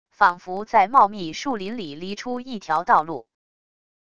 仿佛在茂密树林里犁出一条道路wav音频